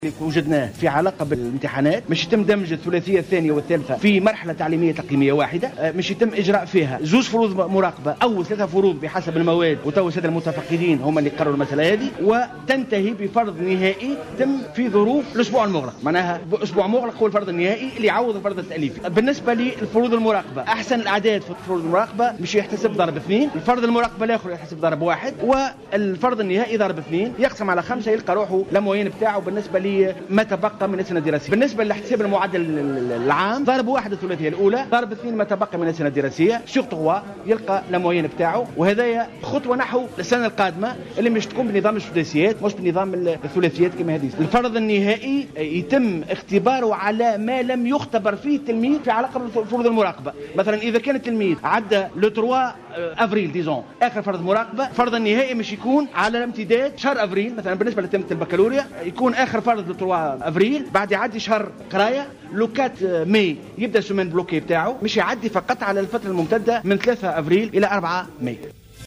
على هامش الندوة الصحفيّة المشتركة مع النقابات العامّة للمتفقّدين والتعليم الثانوي والقيمين